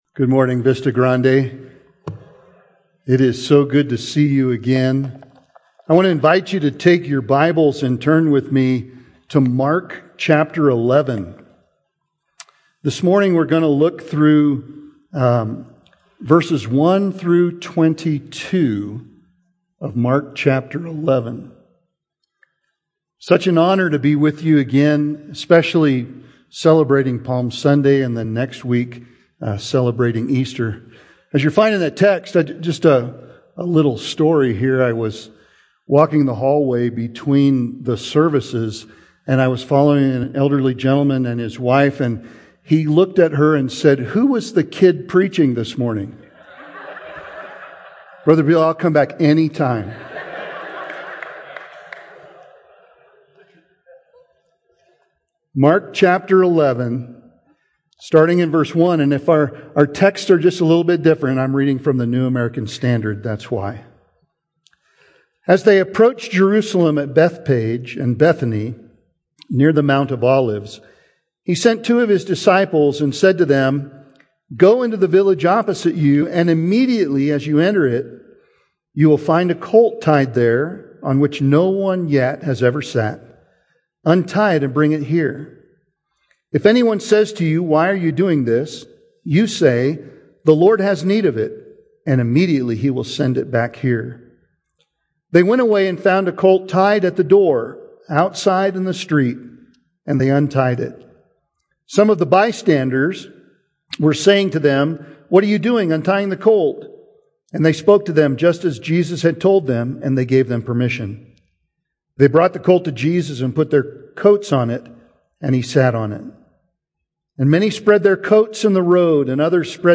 Sermons - Vista Grande Baptist Church